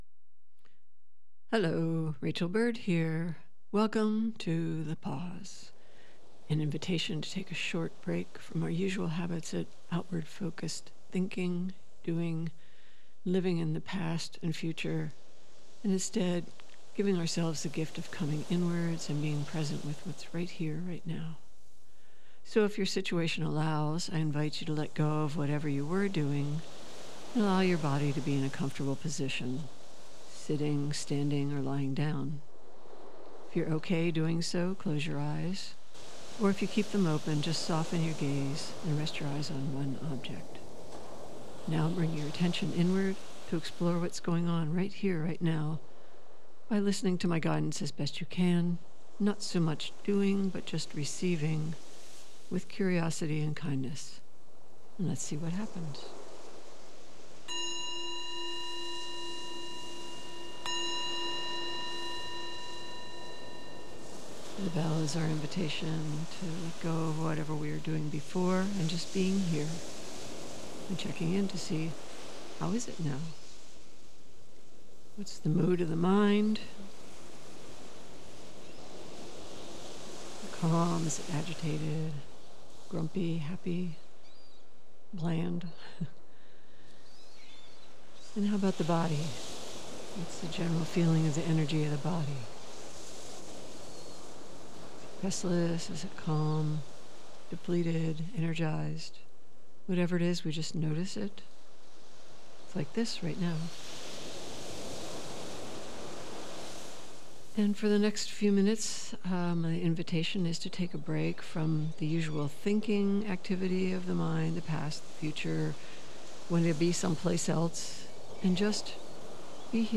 Meditation: noticing what is happening in the present moment.